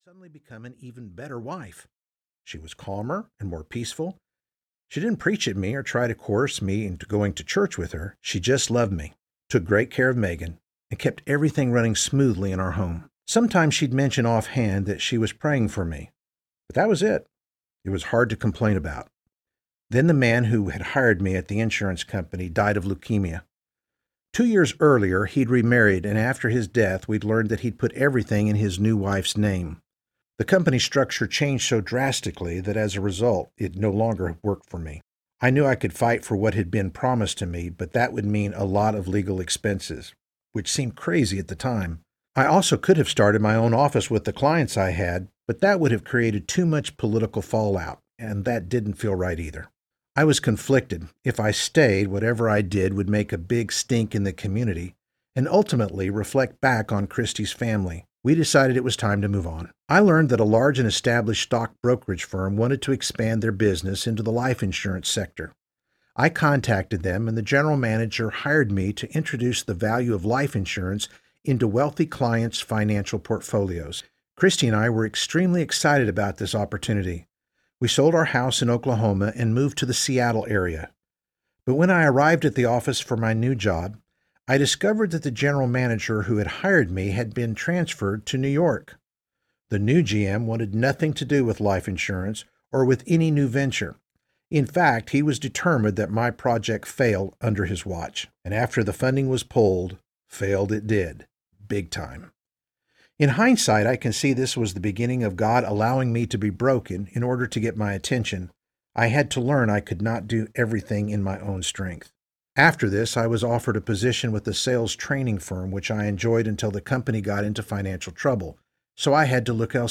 Reclaiming Our Forgotten Heritage Audiobook